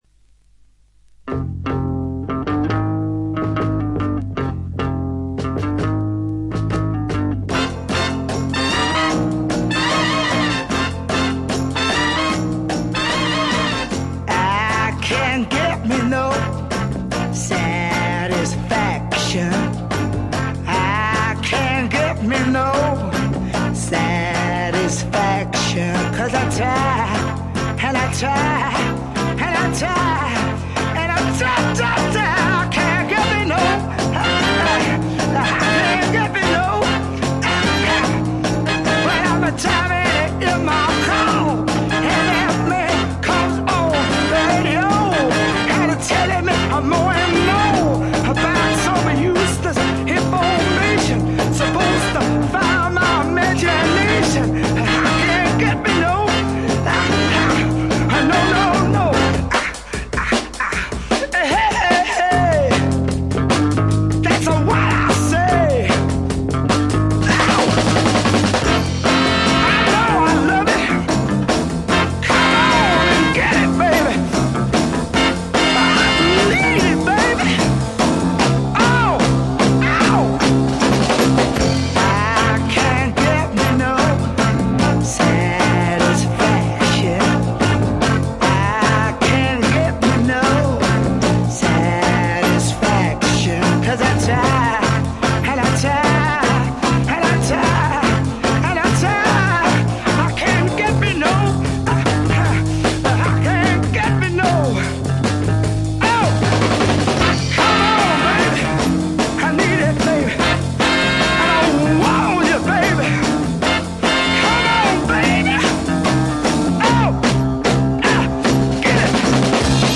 ところどころでチリプチ、バックグラウンドノイズ。特に気になるような大きなノイズはありません。
試聴曲は現品からの取り込み音源です。